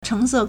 橙色 chéngsè
cheng2se4.mp3